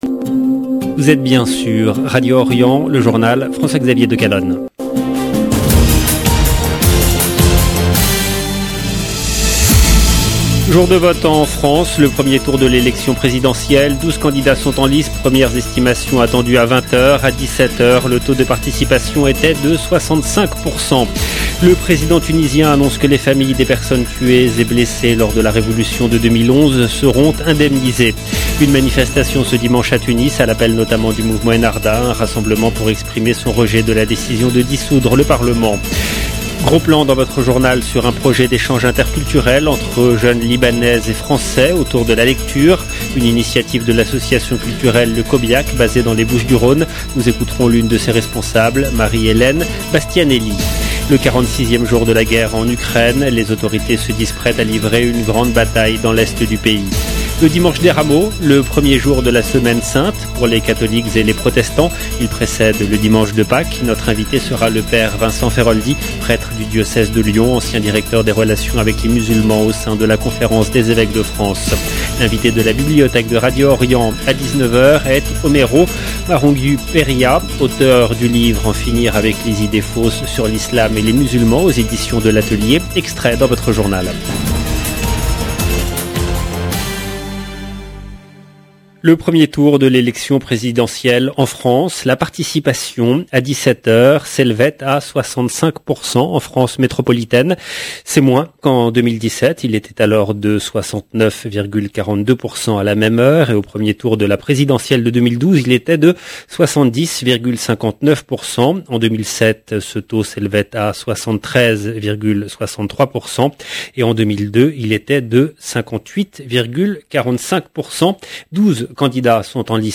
EDITION DU JOURNAL DU SOIR EN LANGUE FRANCAISE DU 10/4/2022